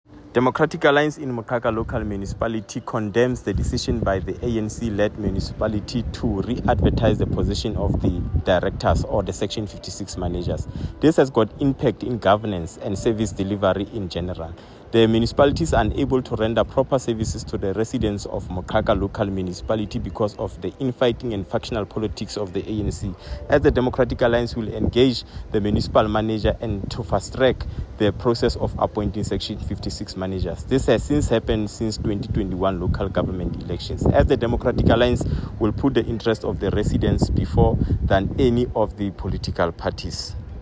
Sesotho soundbites by Cllr David Nzunga.